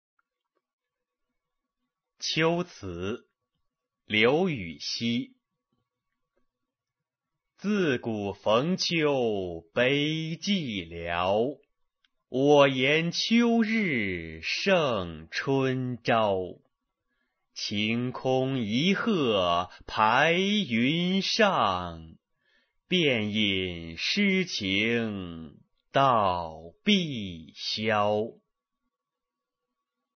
刘禹锡《秋词》原文、译文、赏析（含朗读）